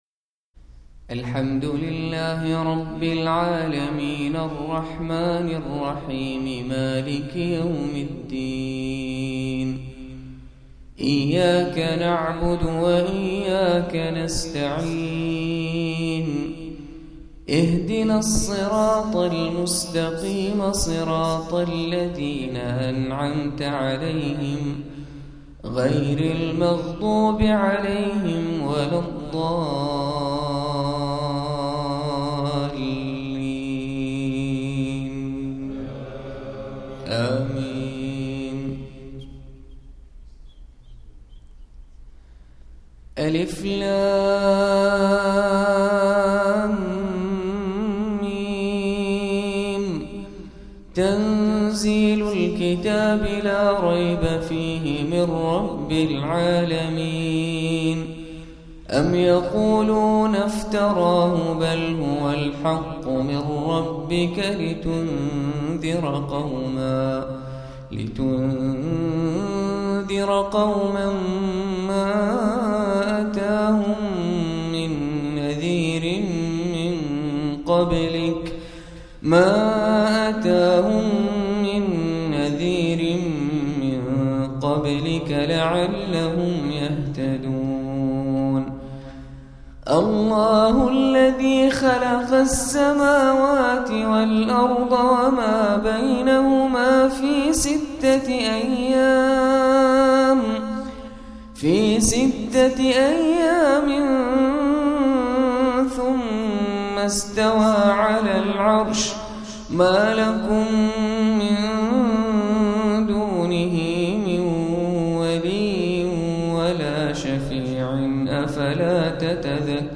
ماتيسر من سورة النحل,فجريات ,فجريات